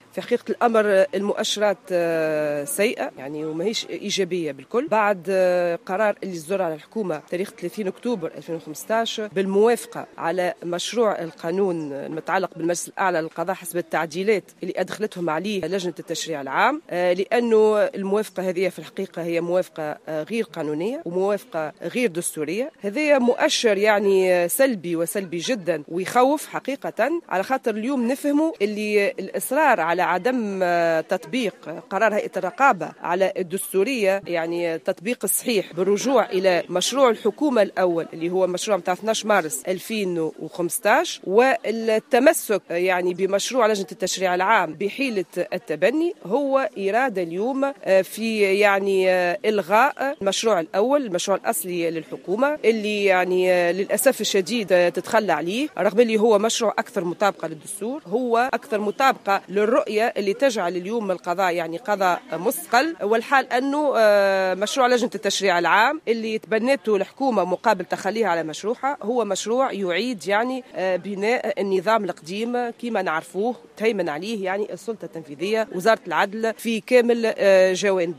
أكدت رئيسة جمعية القضاة التونسيين، روضة القرافي في تصريح لمراسل "الجوهرة أف أم" وجود "ضغوطات سياسية وإعلامية" مسلطة على القضاة في عدد من القضايا من بينها قضية الشهيد شكري بلعيد وقضية جامع اللخمي بصفاقس.
وأضافت القرافي على هامش انعقاد مجلس وطني للمكتب التنفيذي للجمعية أن أطرافا سياسية سواء كانت متهمة أو في موقع الدفاع تمارس ضغطا و تشن حملات سياسية مسنودة إعلاميا ضد القضاء، وفق تعبيرها.